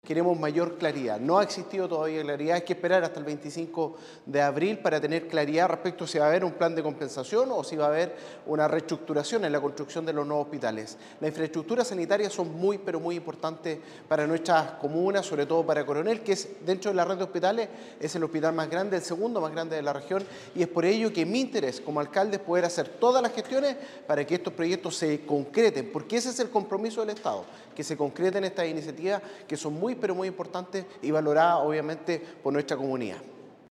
En tanto, el alcalde de Coronel, Boris Chamorro, fue enfático en pedir al Estado “que cumpla su compromiso con nuestros vecinos”.